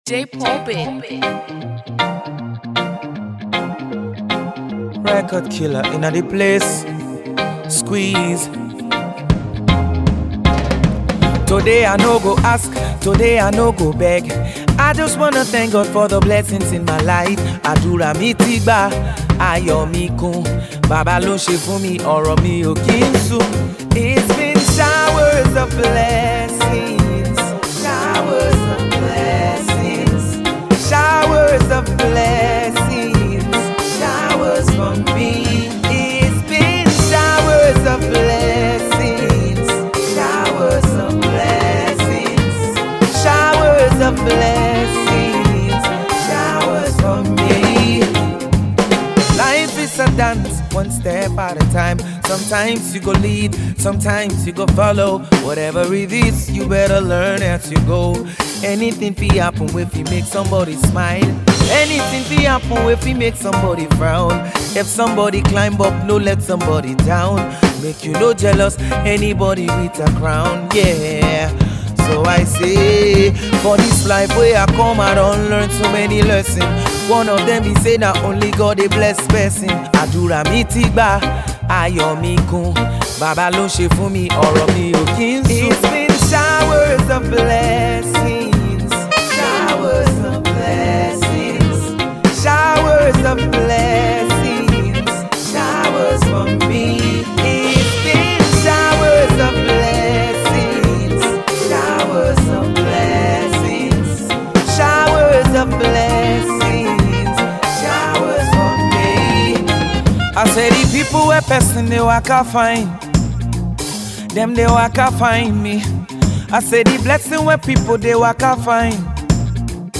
Dancehall
Soft Reggae